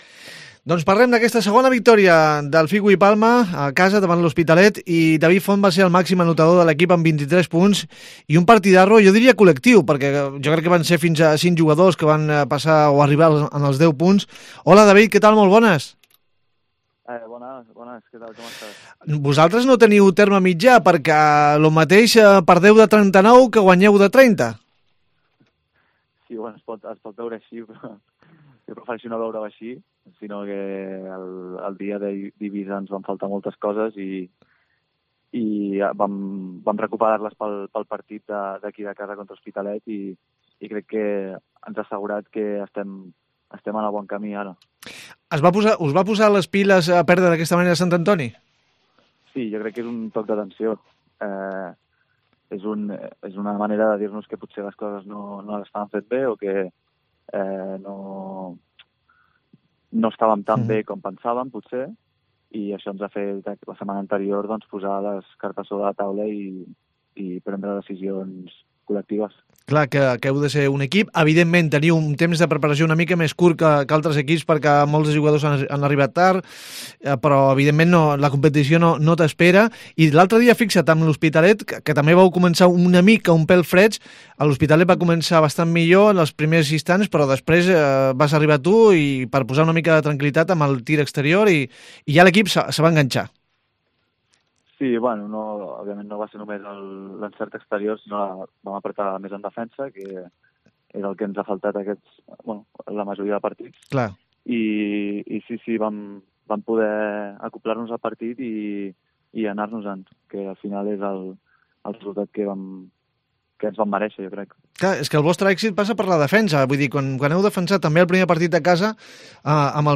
Charlamos con él de una temporada difícil para el Bahía San Agustín, todo un reto buscar retornar a la LEB Oro.